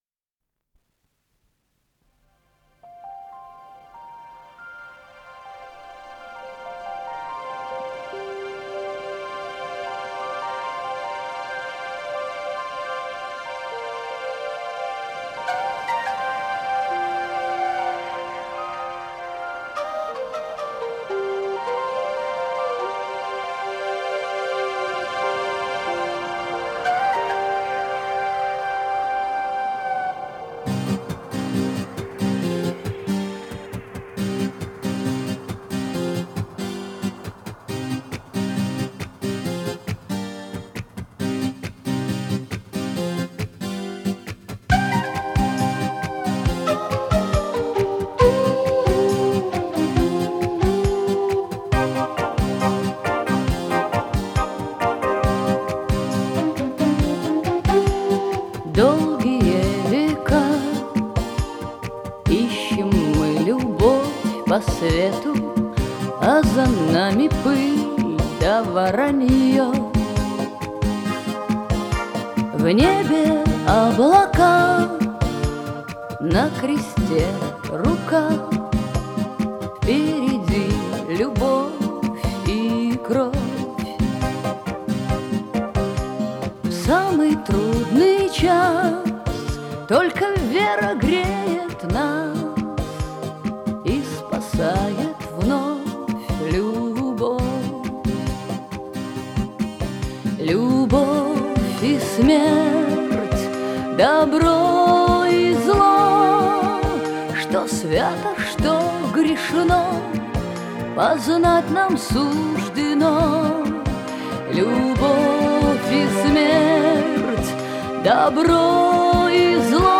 с профессиональной магнитной ленты
Скорость ленты38 см/с
Тип лентыORWO Typ 106